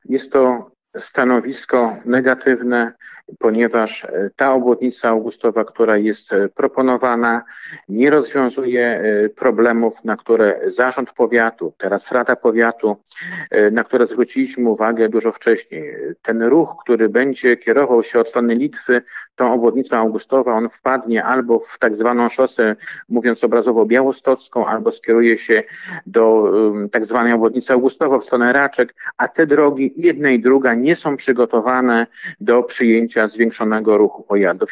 Szczegóły przedstawił Jarosław Szlaszyński, starosta augustowski.